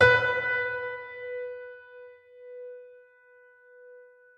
piano-sounds-dev
b3.mp3